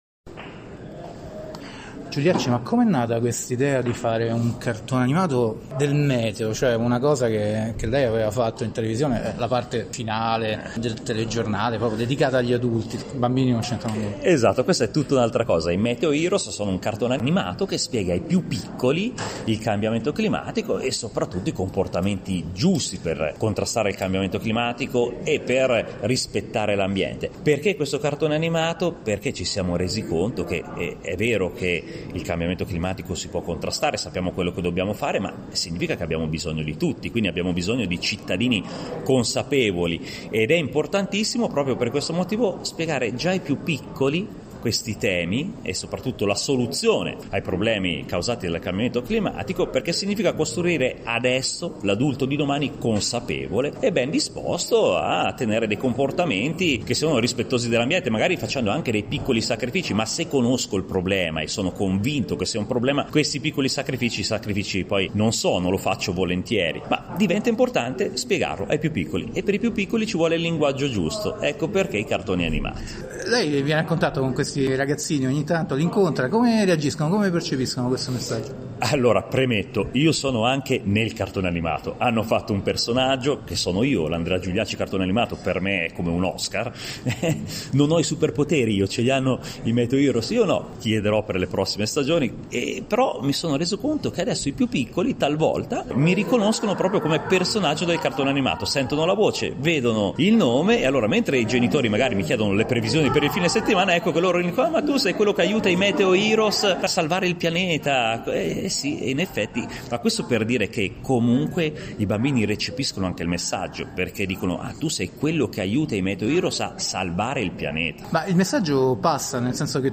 Di seguito la versione integrale dell’intervista a Andrea Giuliacci trasmessa nella rubrica settimanale di Earth Day Italia “Ecosistema”, ospitata nel programma “Il Mondo alla Radio” di Radio Vaticana Italia.